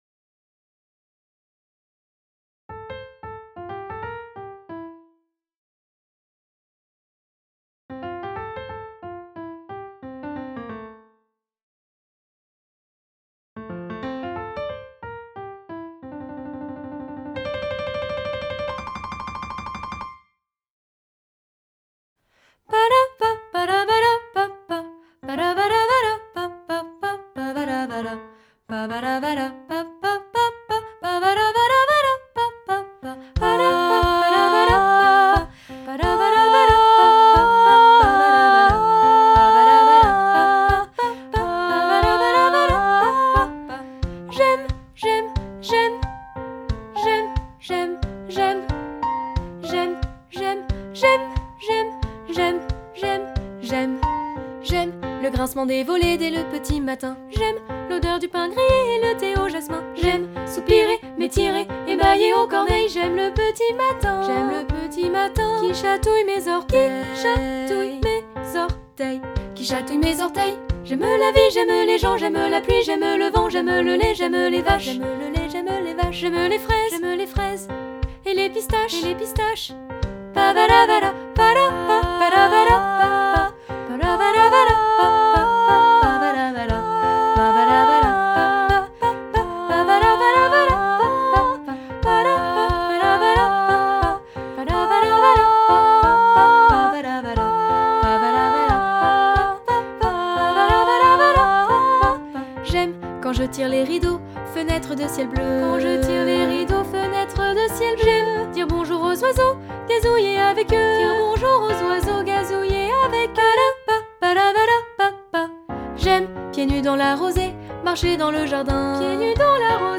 Genre :  Chanson
Style :  Avec accompagnement
Effectif :  PolyphonieVoix égales
Enregistrement piano et voix